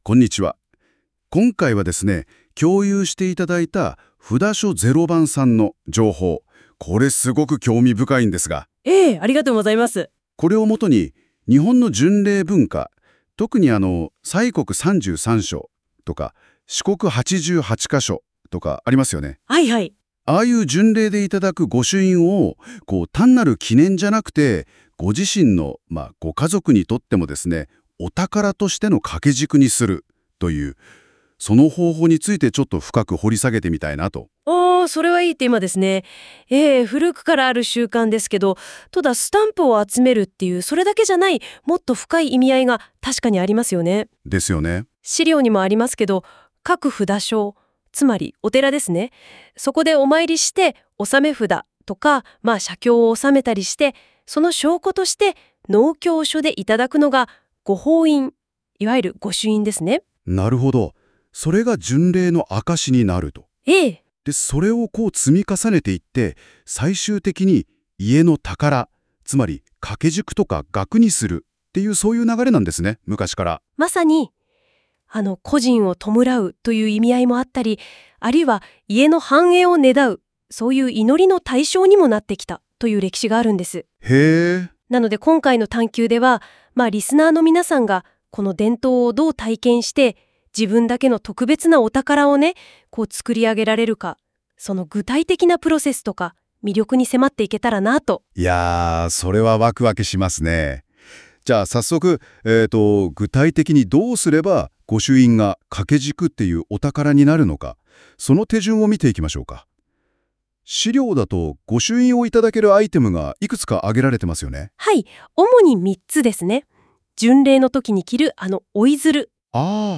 AIによるボイスでのこのページの説明を作って見ました。ところどころ固有名詞の発音が完全でない所もありますが、とても参考になります。